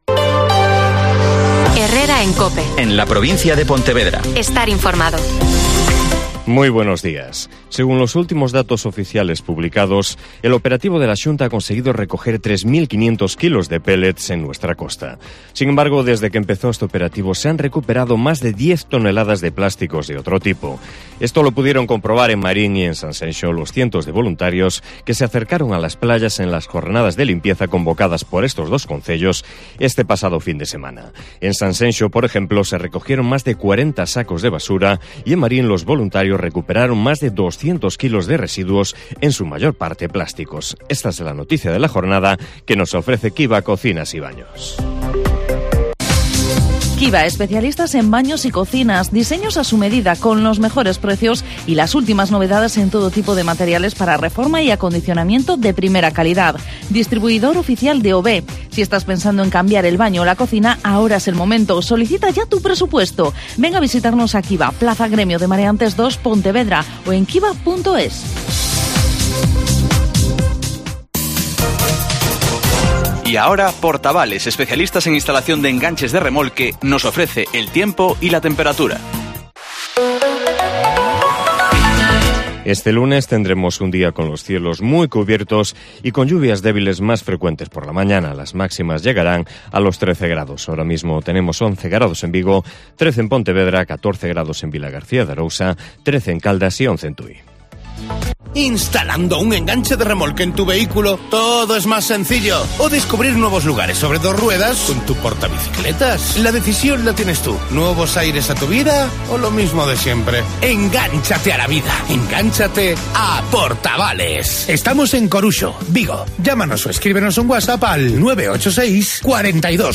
Herrera en COPE en la Provincia de Pontevedra (informativo 08:24h)